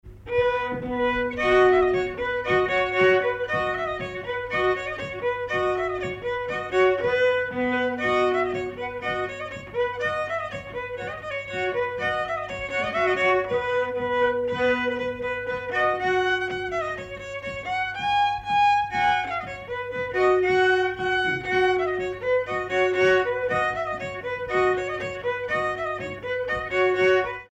Villard-sur-Doron
Pièce musicale inédite